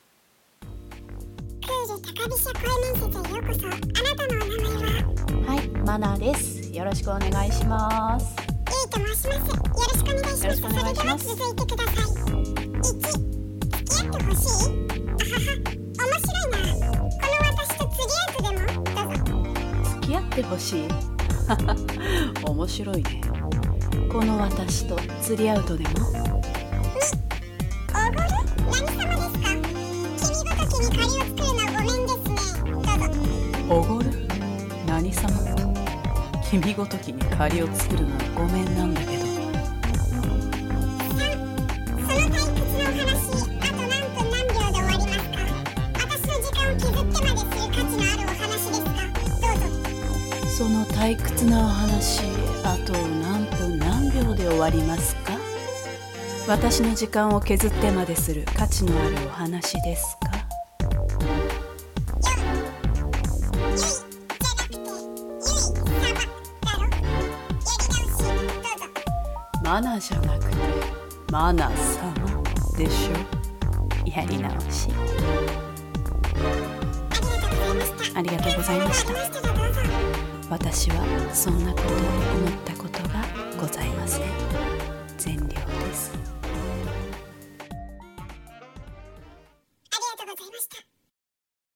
クール高飛車声面接